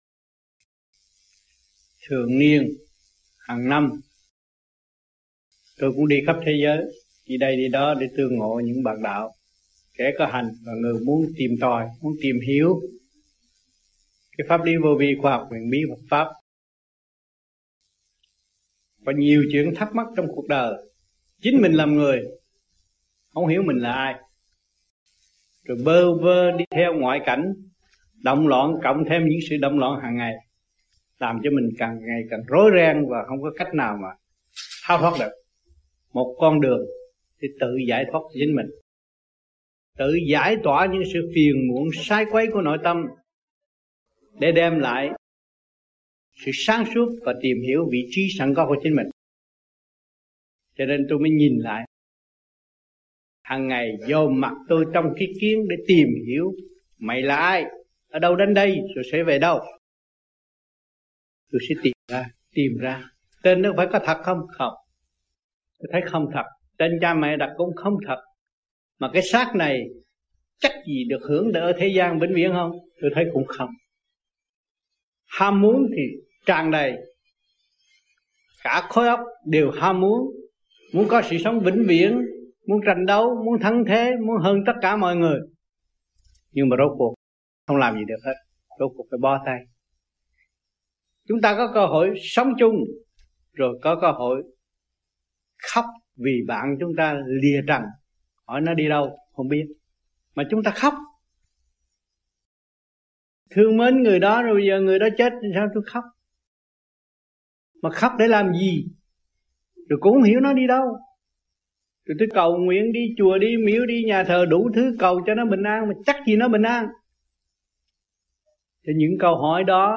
1990-05-20 - PERTH - THẦY THUYẾT GIẢNG CHO CỘNG ĐỒNG VIỆT NAM TẠI PERTH